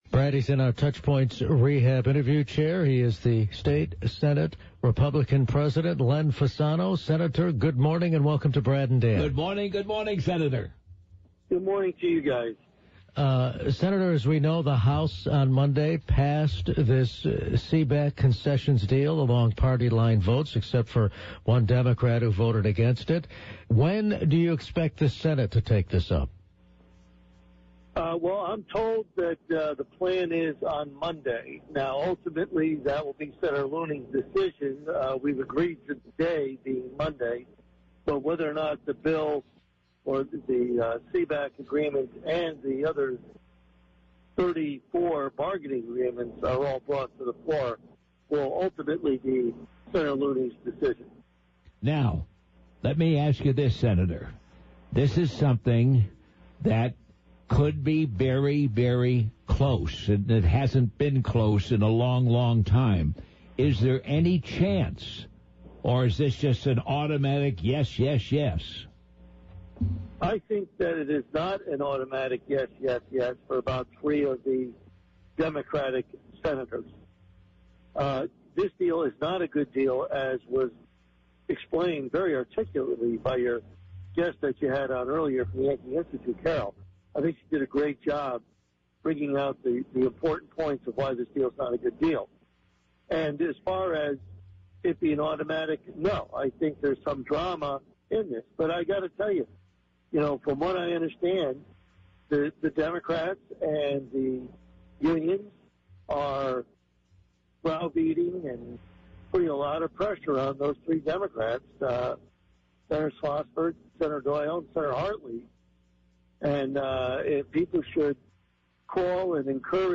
Connecticut Senate Republican President Len Fasano says the state budget is at a crossroads, with the state public employees' union concession deal approved by the House Democrats. In this interview, he explains why the Republicans oppose the deal and speculates when or whether it will come up for a vote in the Senate.